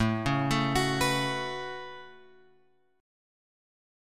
A 6th Add 9th